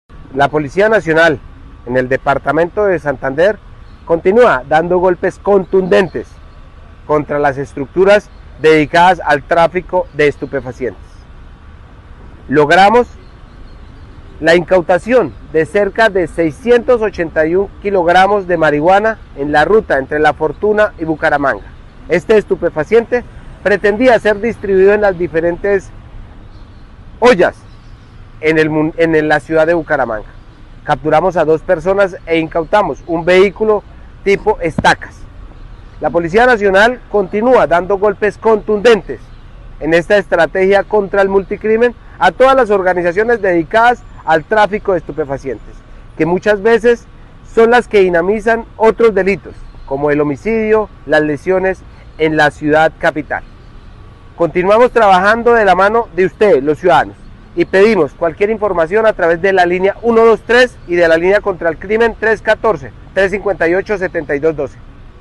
coronel Néstor Rodrigo Arévalo, Comandante Departamento de Policía Santander